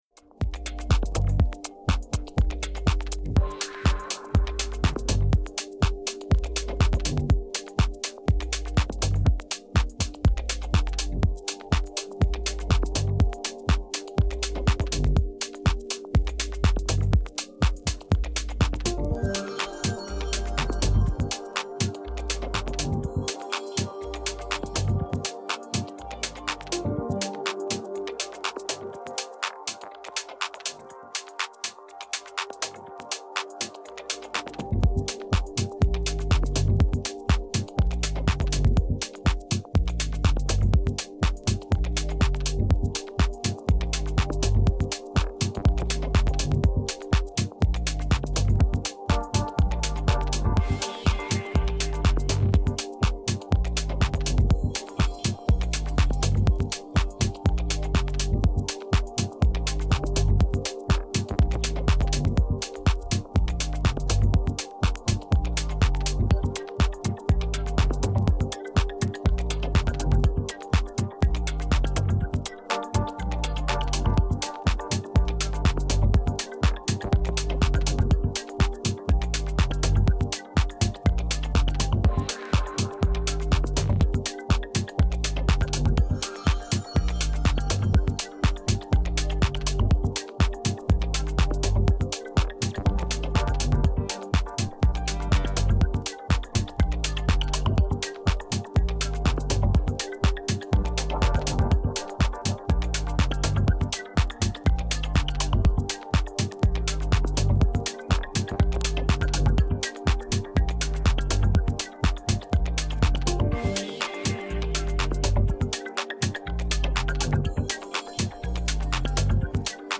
dj set